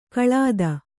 ♪ kaḷāda